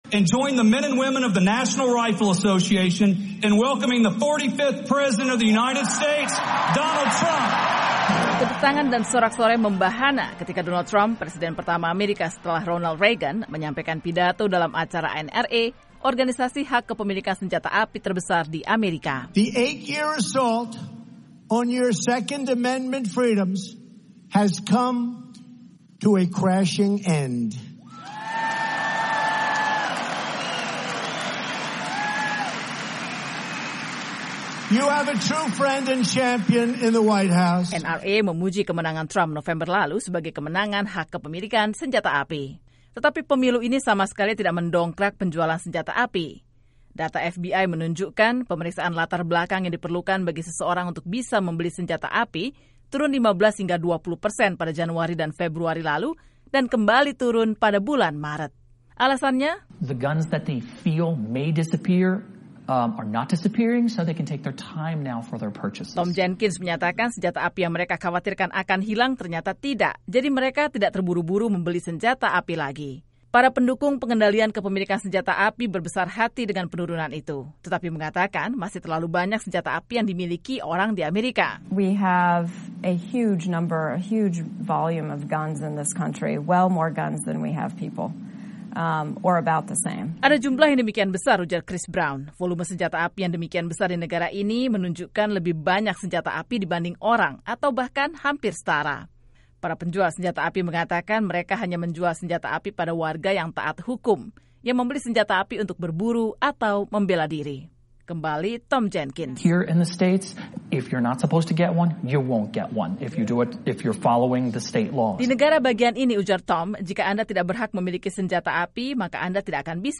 menyampaikan laporannya.